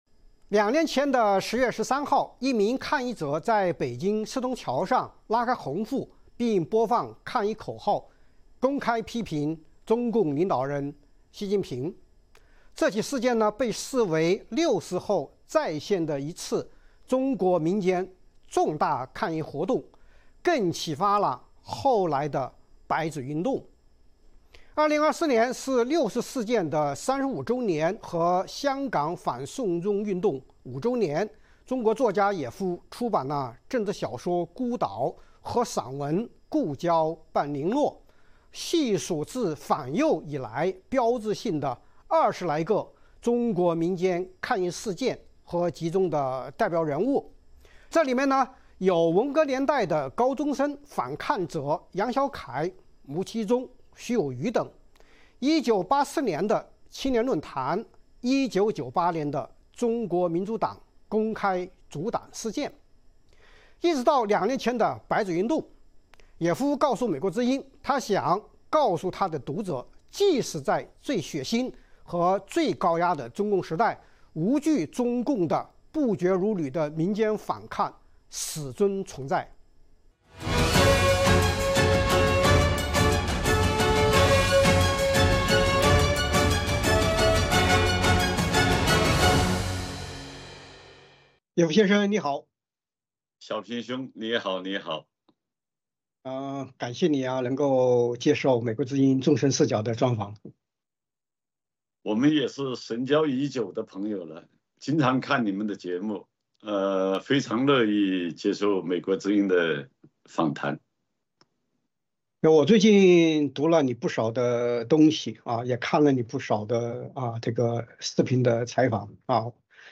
专访野夫：中共建政后的民间反抗事件和人物
作家野夫借出版政治小说《孤岛》和散文《故交半零落》之际，细数近60年来在中国发生的20余件中国民间抗议事件和代表人物，其中有“文革”年代的高中生反抗者杨小凯、牟其中、徐友渔、1984年创刊的《青年论坛》、1998年中国民主党组党事件和为政治犯妻儿送饭的民间救助 “送饭党运动”等。 《纵深视角》节目进行一系列人物专访，受访者发表的评论不代表美国之音的立场。